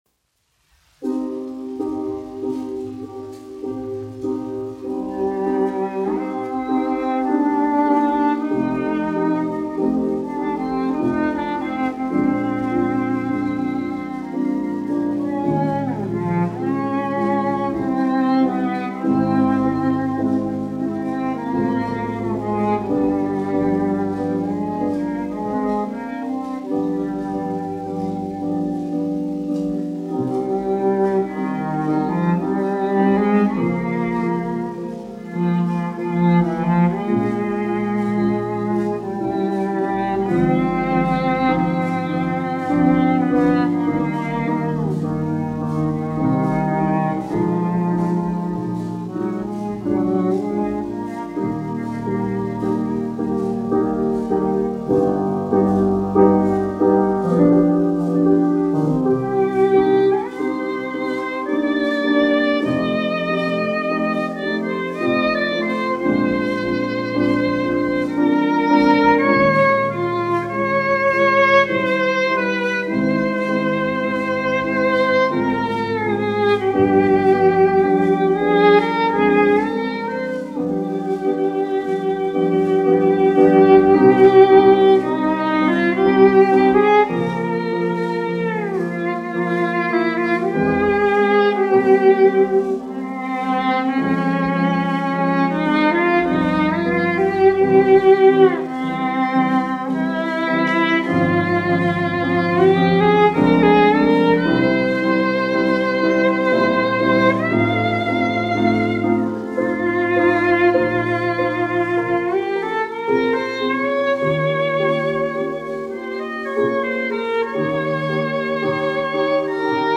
1 skpl. : analogs, 78 apgr/min, mono ; 25 cm
Čella un klavieru mūzika, aranžējumi
Skaņuplate
Latvijas vēsturiskie šellaka skaņuplašu ieraksti (Kolekcija)